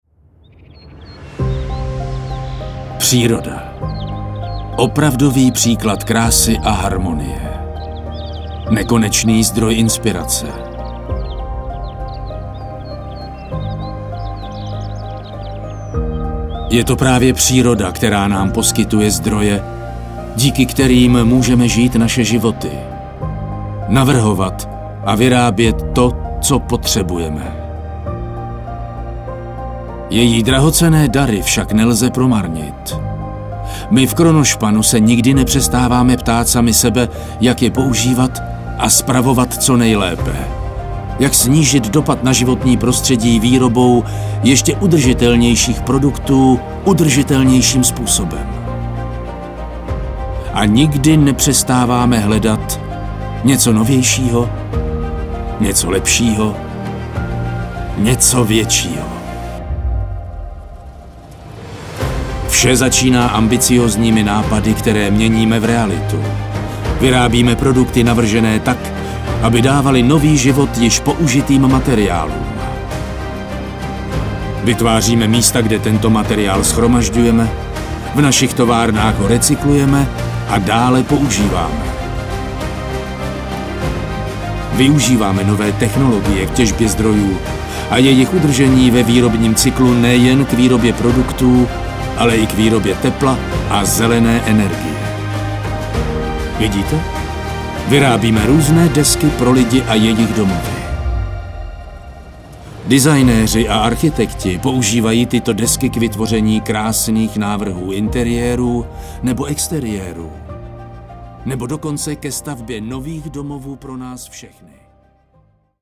ukázka 01 – Voice over / Reklama